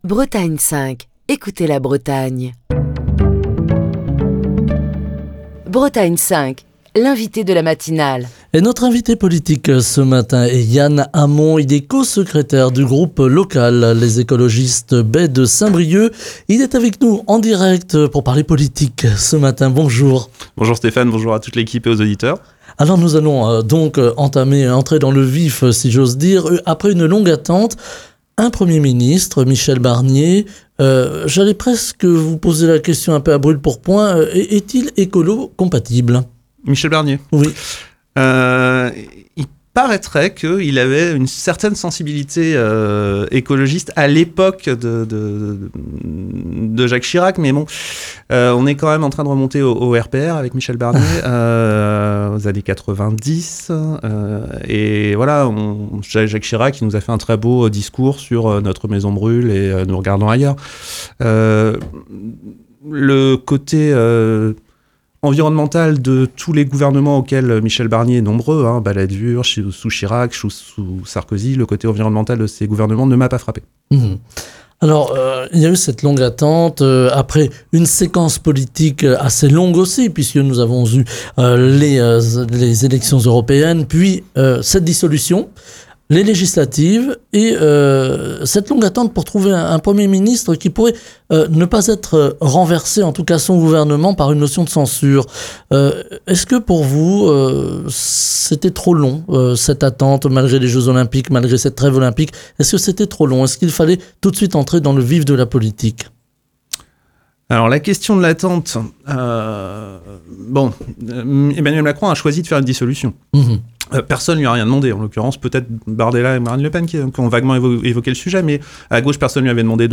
est l'invité politique de la matinale de Bretagne 5 pour commenter l'actualité politique et la nomination de Michel Barnier au poste de Premier ministre.